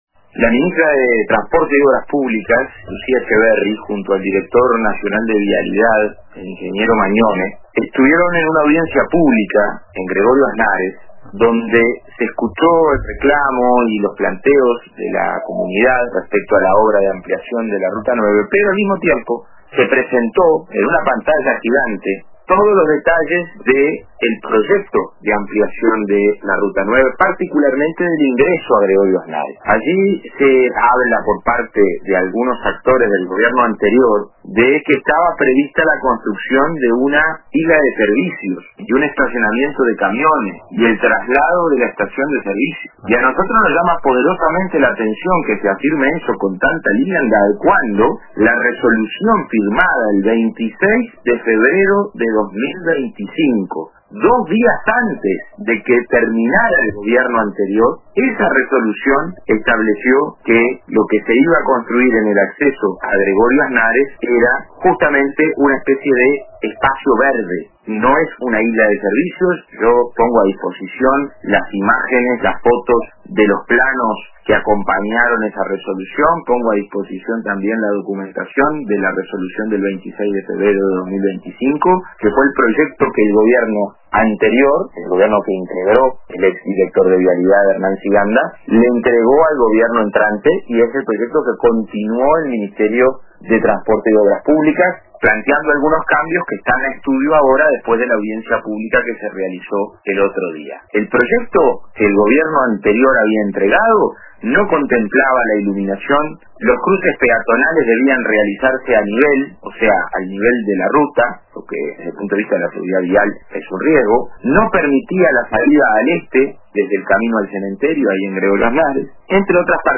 El diputado del Frente Amplio por Maldonado, Joaquín Garlo, participó en el programa “Radio con Todos” de Radio RBC, donde se refirió al proyecto de doble vía de la Ruta 9, particularmente en el tramo de acceso a Gregorio Aznárez.